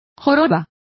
Complete with pronunciation of the translation of hunchback.